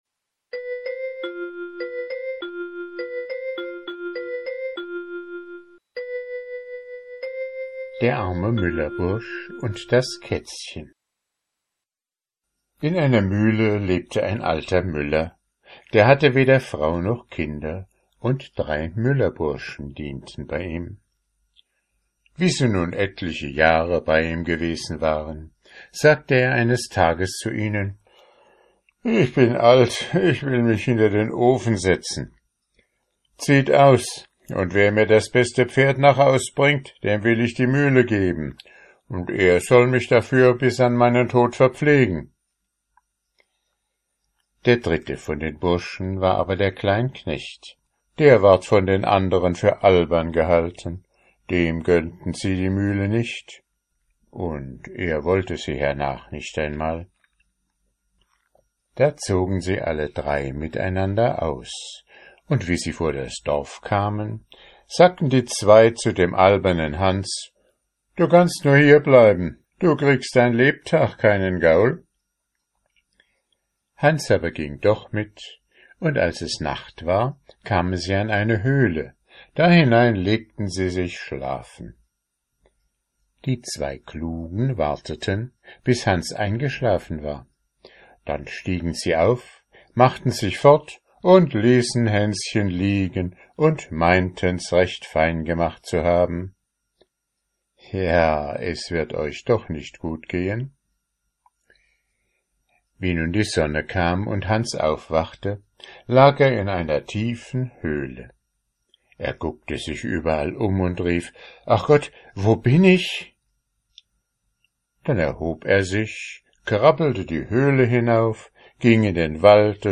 Vorlesezeit 11 min ✓ Alle Grimm und Andersen Märchen in Originalfassung ✓ Online Märchenbuch mit Illustrationen ✓ Nach Lesedauer sortiert ✓ Mp3-Hörbücher ✓ Ohne Werbung